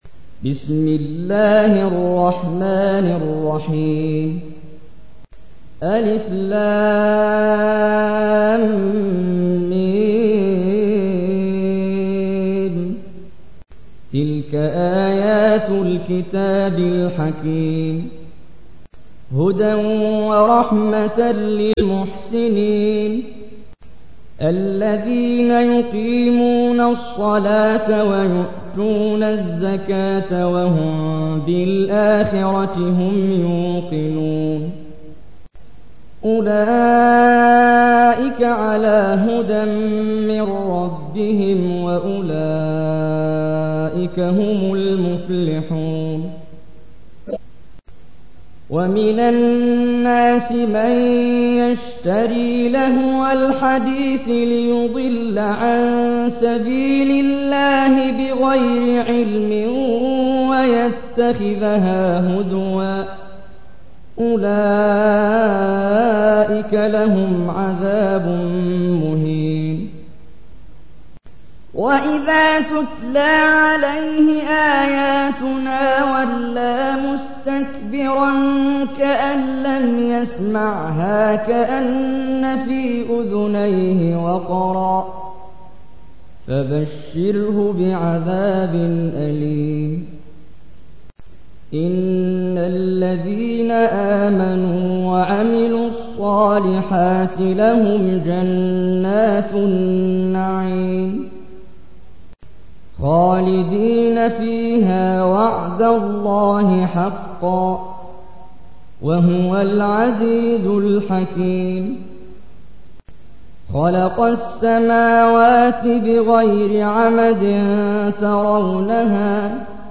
تحميل : 31. سورة لقمان / القارئ محمد جبريل / القرآن الكريم / موقع يا حسين